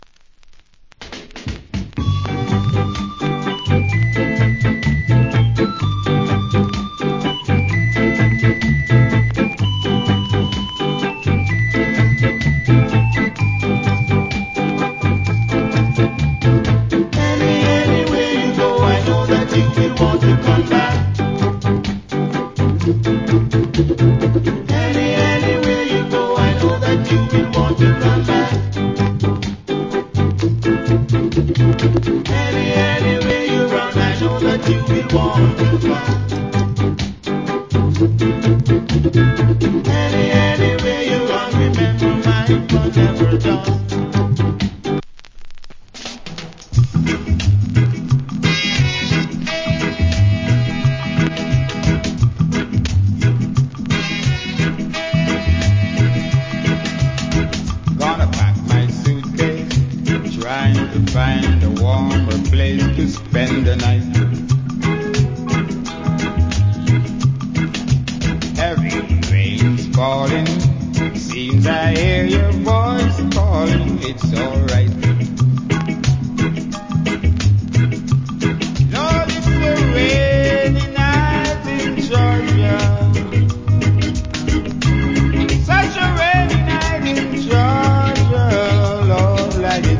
category Ska
Wicked Early Reggae Vocal. / Good Early Reggae Vocal.